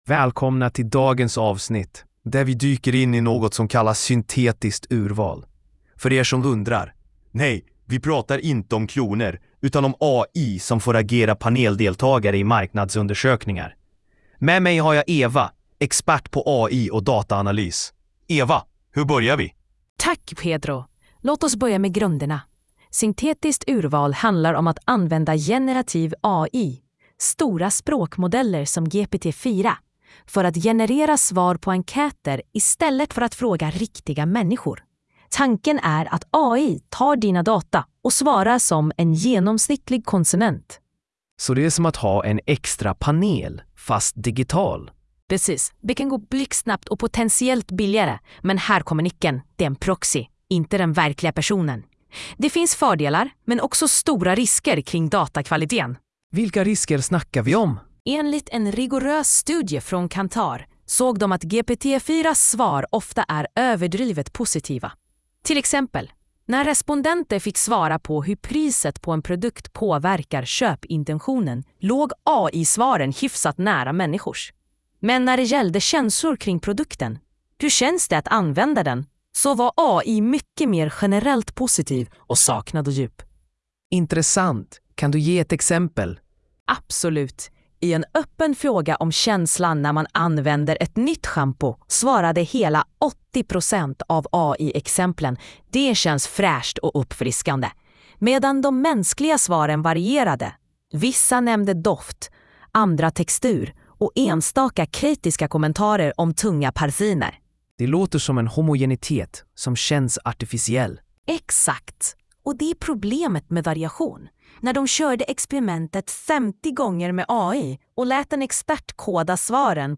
Lyssna på avsnittet genom en AI-genererad podcast: